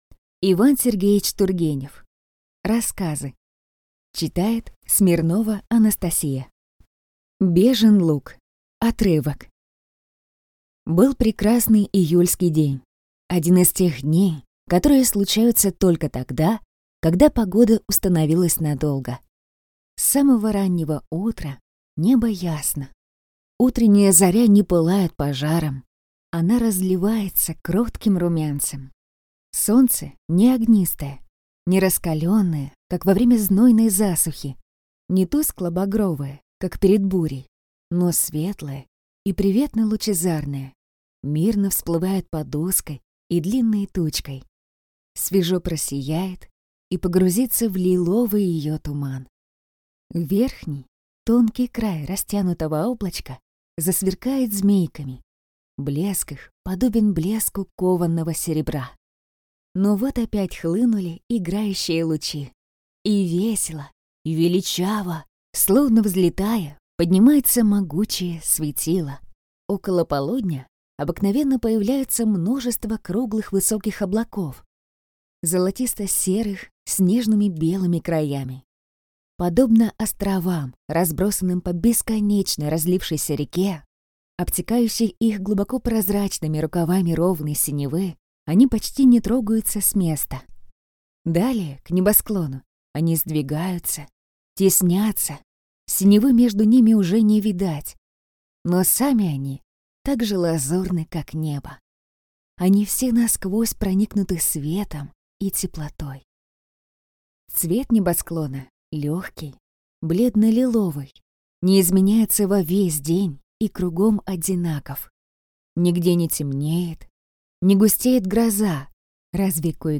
Аудиокнига Рассказы | Библиотека аудиокниг
Прослушать и бесплатно скачать фрагмент аудиокниги